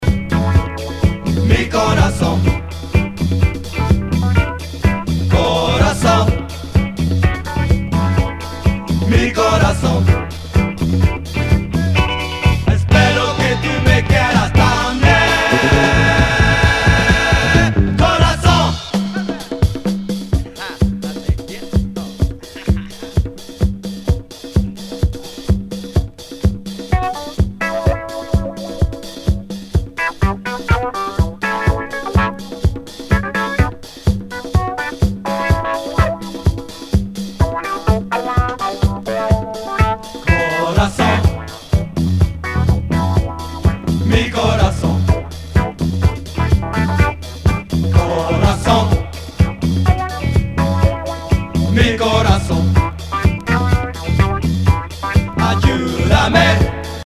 SOUL/FUNK/DISCO
ナイス！ラテン・ディスコ！
少し盤に歪みあり